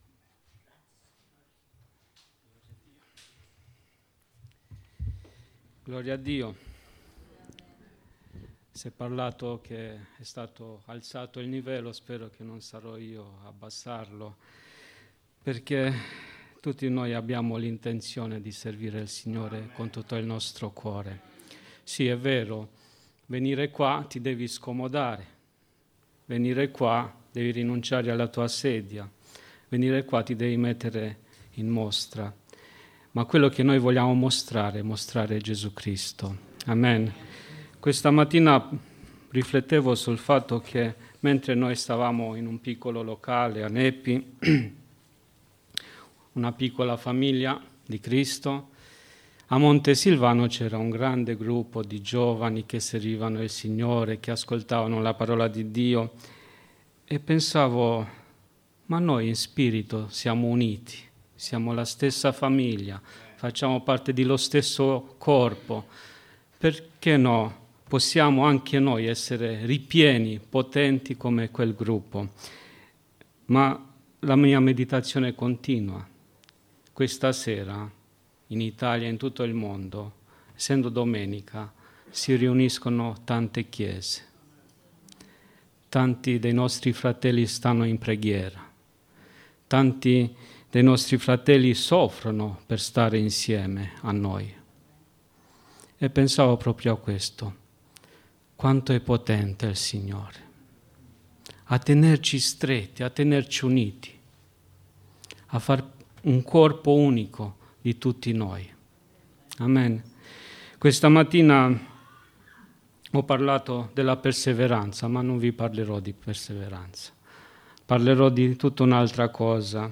Predicatore